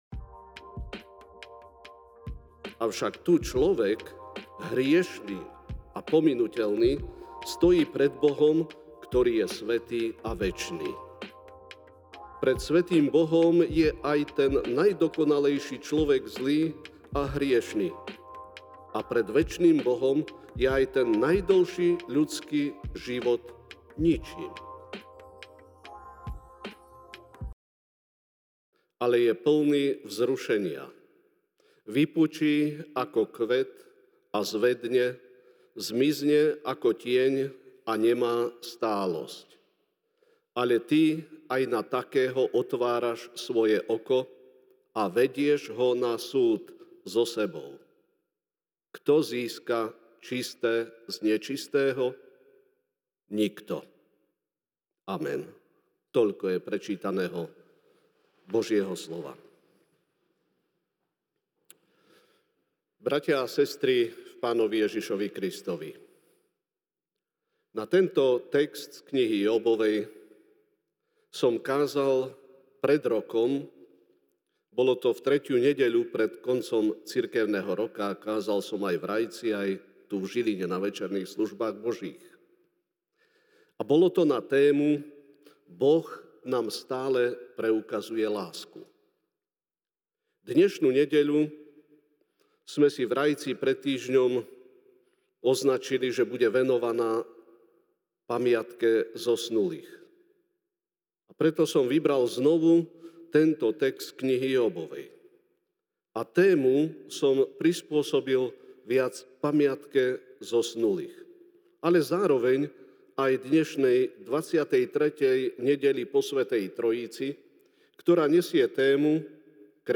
nov 03, 2024 Kresťan občanom neba i zeme MP3 SUBSCRIBE on iTunes(Podcast) Notes Sermons in this Series Večerná kázeň: Jób(14, 1-4) „ Človek narodený zo ženy žije krátko, ale je plný vzrušenia.